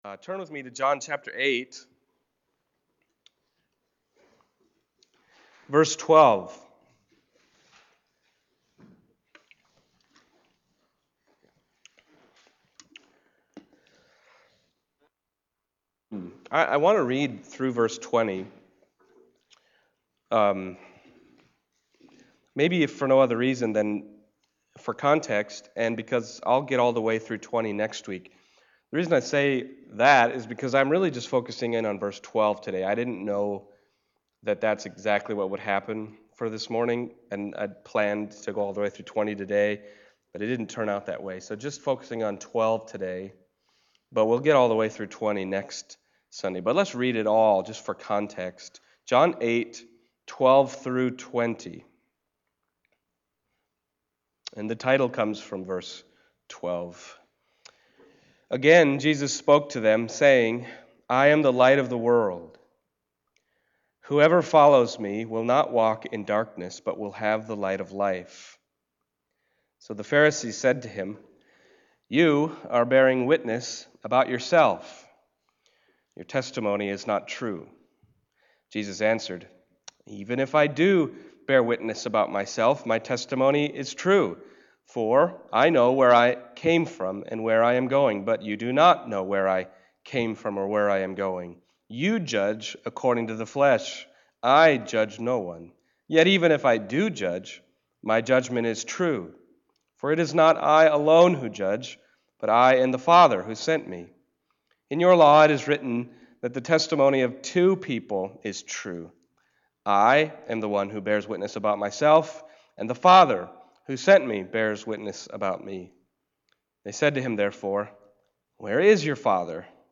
John 8:12-20 Service Type: Sunday Morning John 8:12-20 « Justice and Mercy